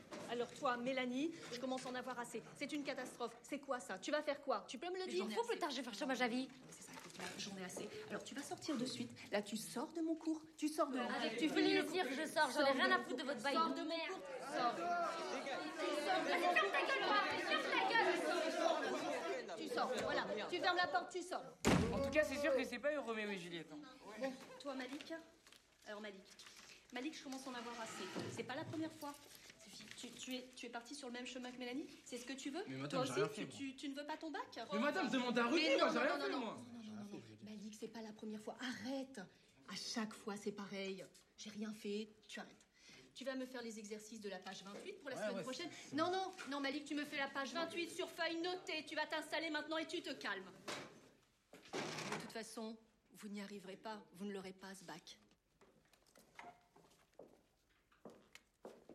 La professeure de français
La situation de base est identique à la précédente mais le discours est ici défaitiste. On sent que l’enseignante ne croit plus en ses élèves (pour conclure son discours, un péremptoire : « De toute façon, vous ne l’aurez pas ce bac ! »).
2.-Prof-de-francais.mp3